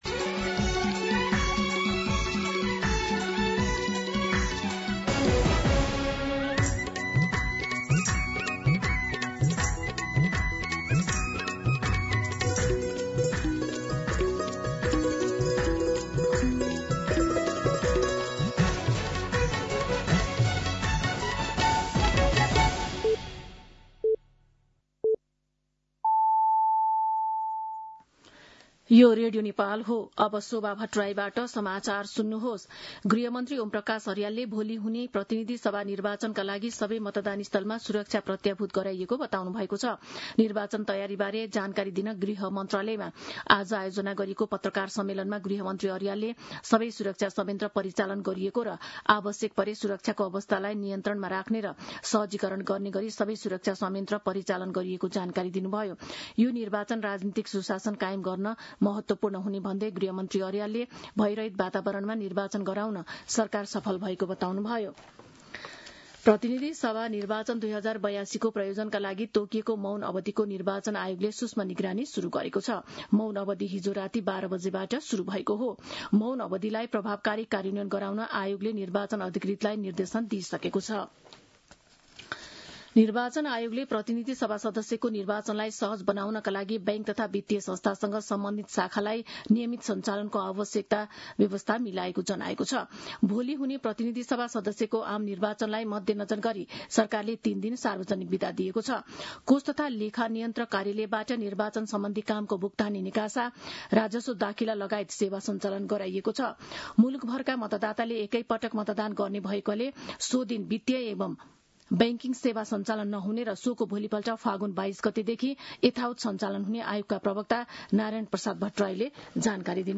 दिउँसो १ बजेको नेपाली समाचार : २० फागुन , २०८२